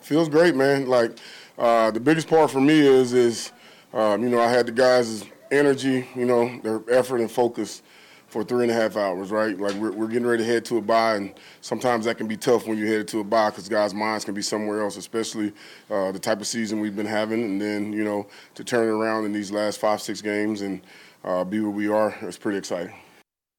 Interim head coach
spoke with media following the win on Saturday night.